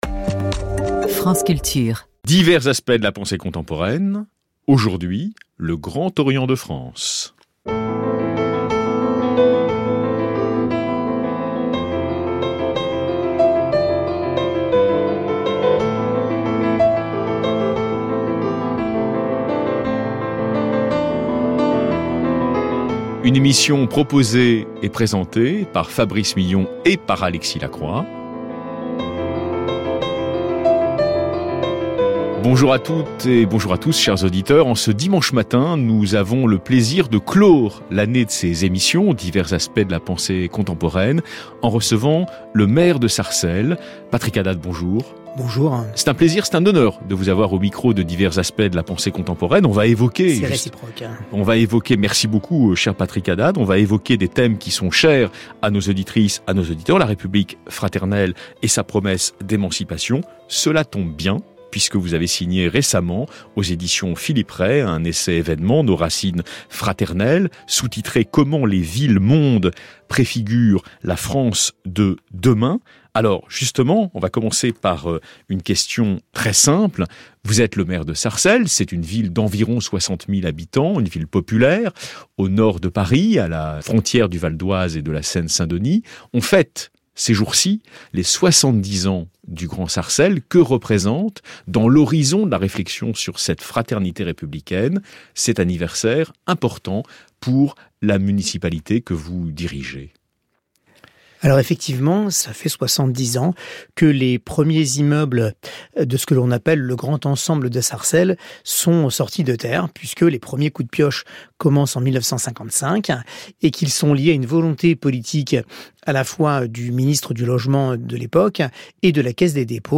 Invité : Patrick HADDAD, Maire de Sarcelles
emission-radio-juin25.mp3